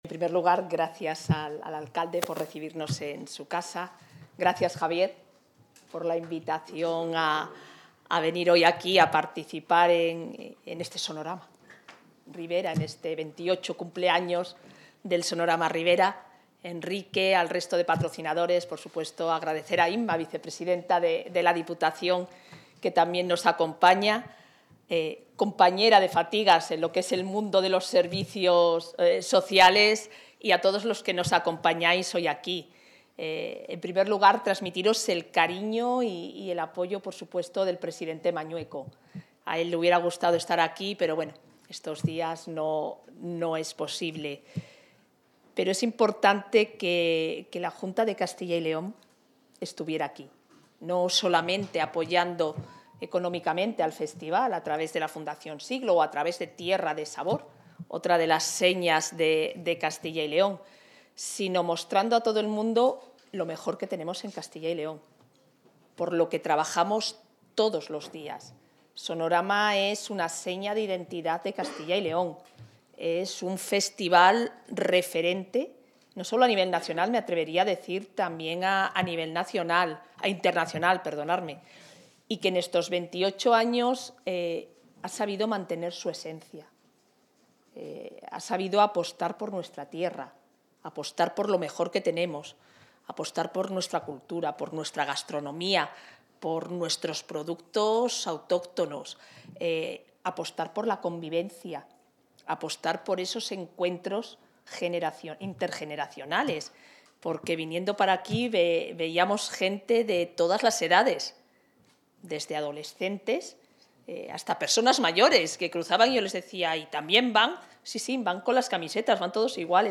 Intervención de la vicepresidenta de la Junta.
La vicepresidenta del Gobierno autonómico ha participado hoy en Aranda de Duero (Burgos) en la presentación de la convocatoria musical anual, en la que se dan cita estos días reconocidos artistas del panorama nacional e internacional.